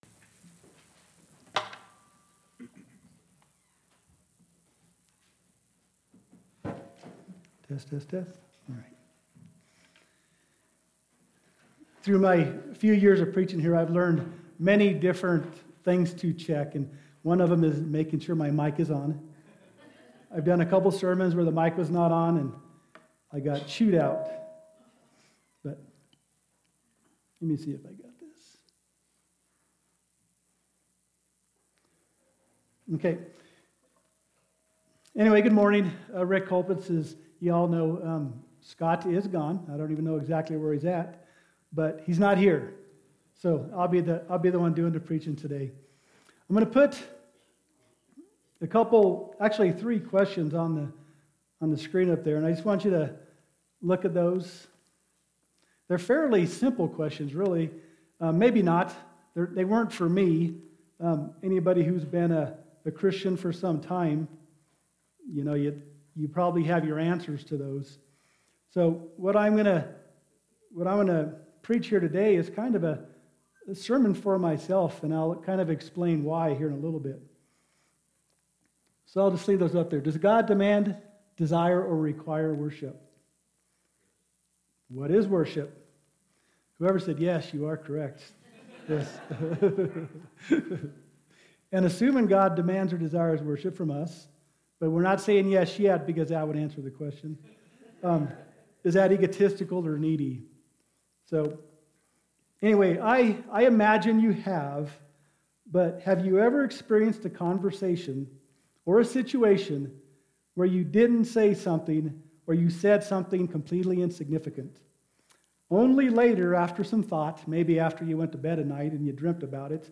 Sermon | Ouray Christian Fellowship